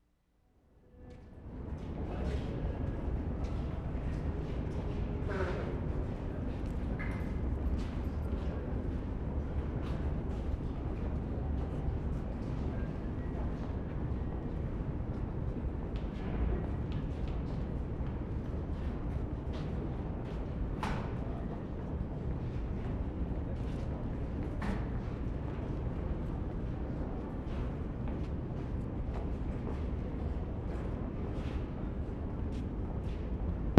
Paris, France April 8/75
6.  NEW TRAIN ARRIVING AT CONCORDE
(false take)